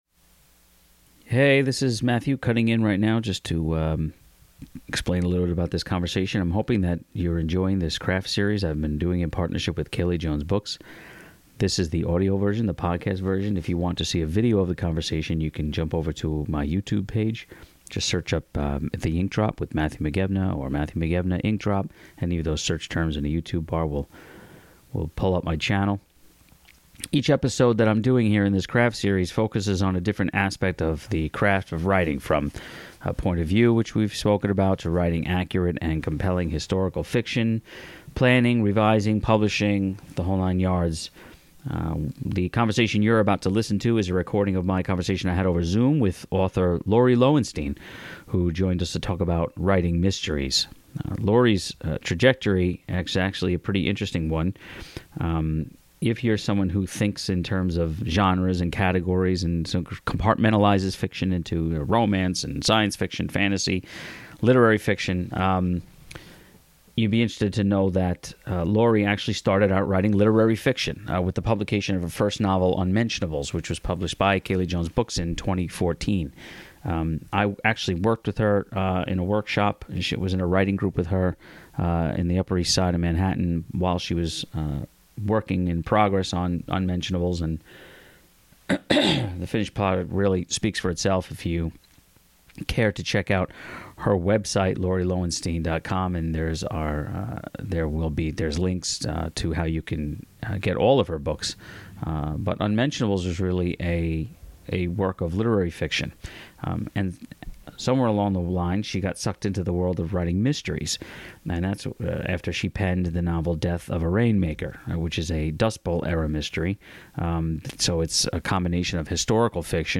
She also stuck around to answer some rapid fire questions at the end of this interview.&nbsp